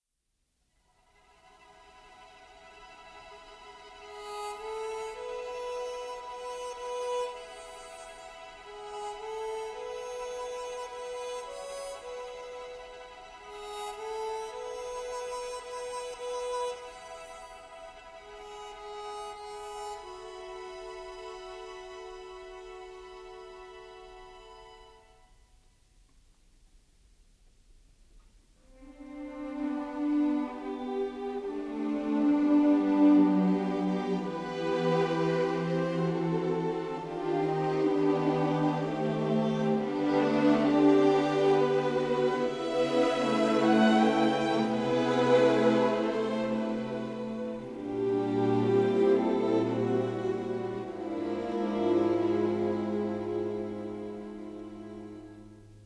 tuneful Americana